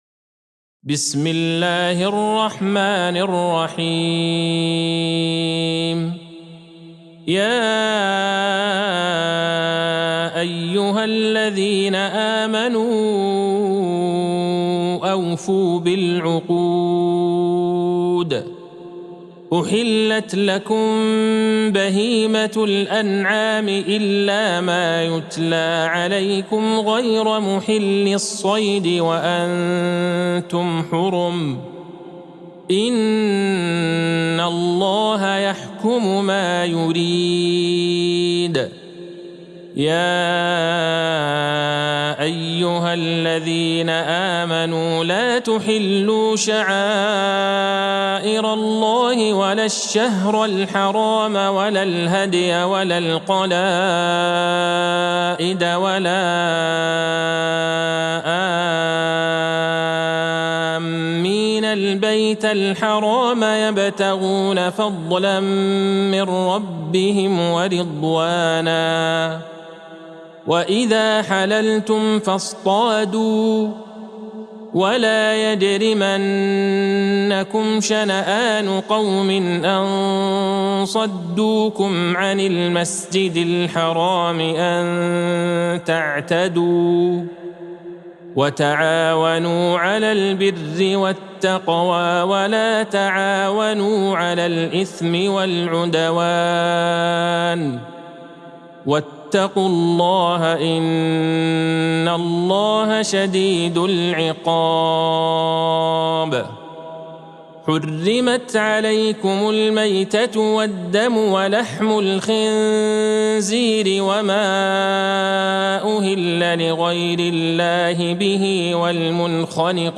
سورة المائدة Surat Al-Ma'idah | مصحف المقارئ القرآنية > الختمة المرتلة ( مصحف المقارئ القرآنية) للشيخ عبدالله البعيجان > المصحف - تلاوات الحرمين